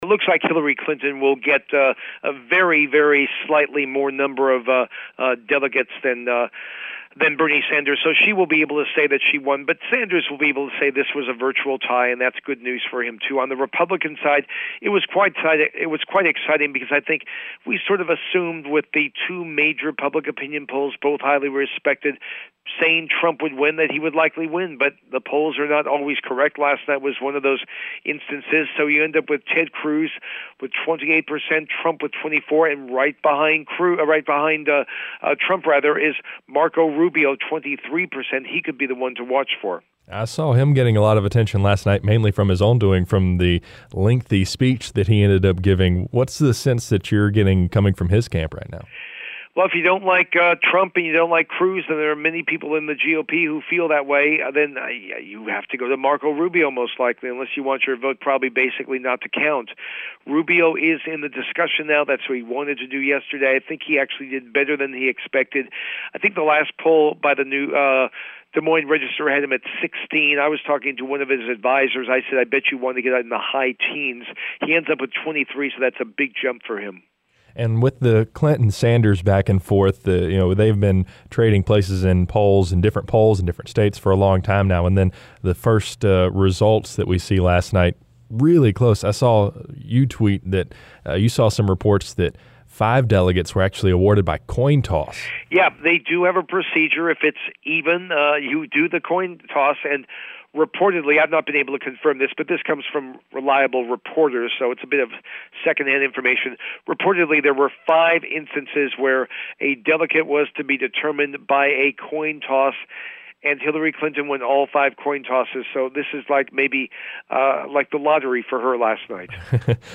CBS News Correspondent